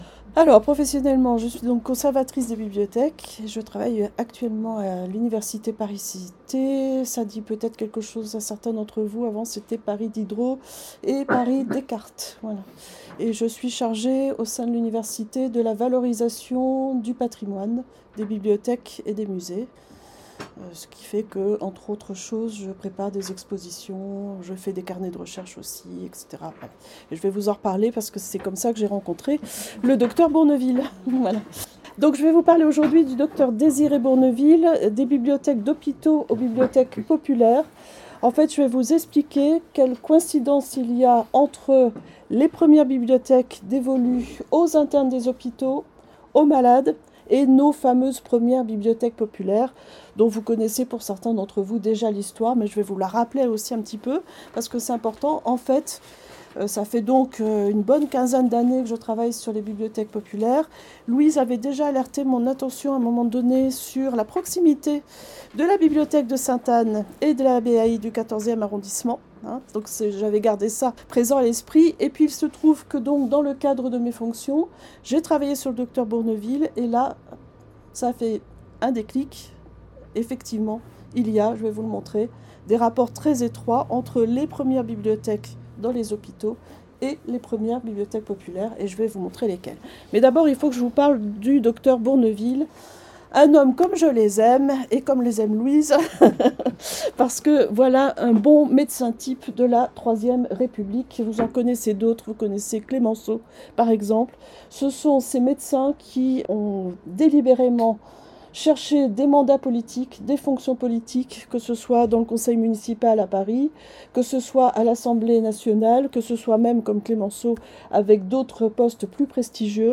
Conférence du 7 février 2026